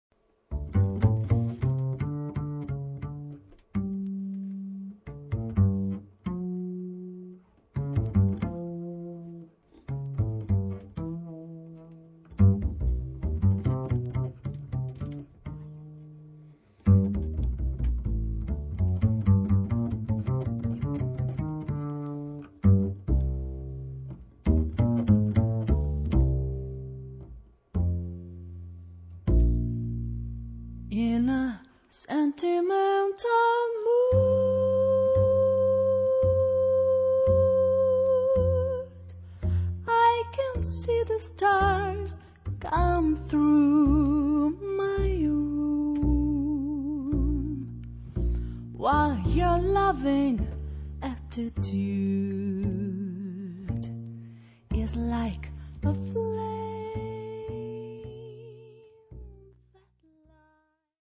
la registrazione predilige toni molto soft, empatici